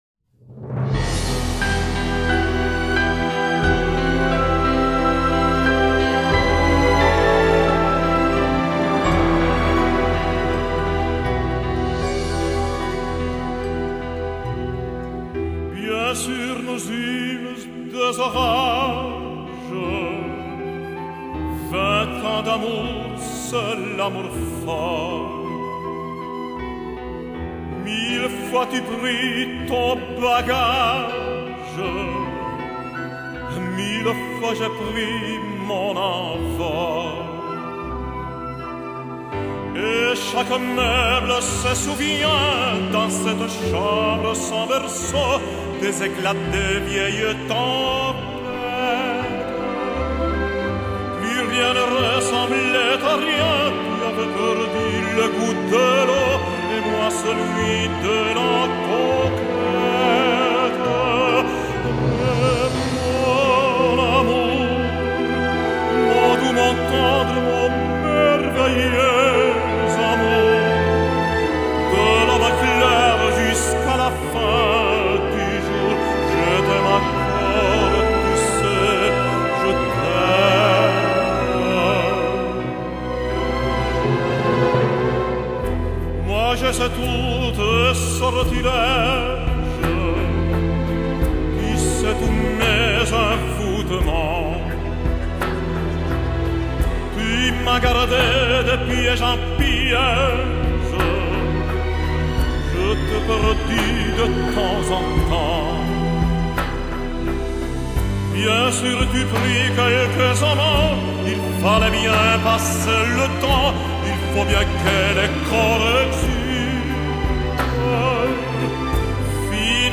Genre: Pop,Classical
他是抒情型男高音，并且擅长演唱音乐剧，其招牌戏是比才的《卡门》和伯恩斯坦的音乐剧《西区故事》。